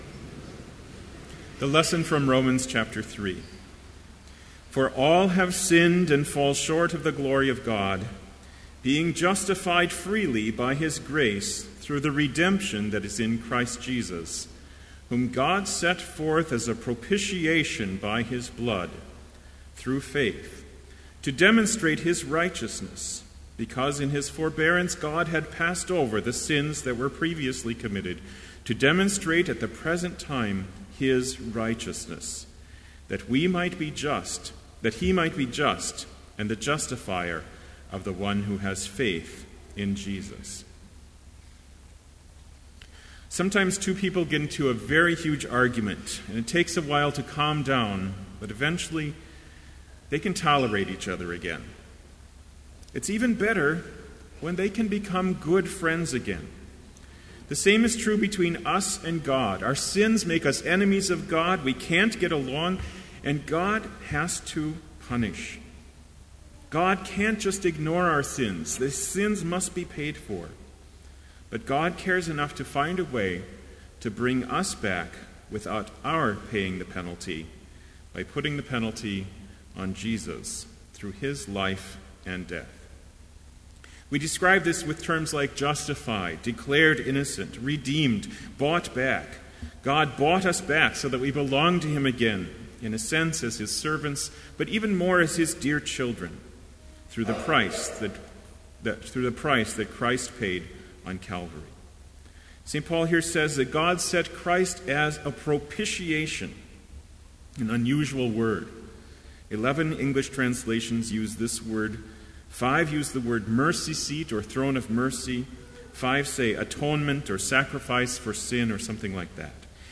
Sermon Only
This Chapel Service was held in Trinity Chapel at Bethany Lutheran College on Tuesday, February 18, 2014, at 10 a.m. Page and hymn numbers are from the Evangelical Lutheran Hymnary.